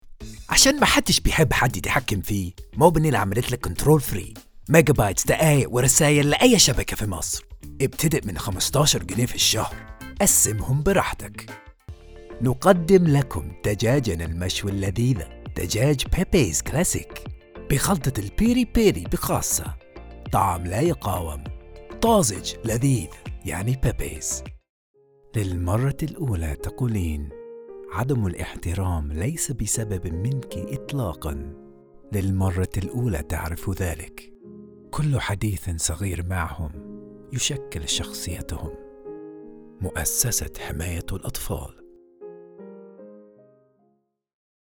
Arabic Showreel
Male
Confident
Cool
Friendly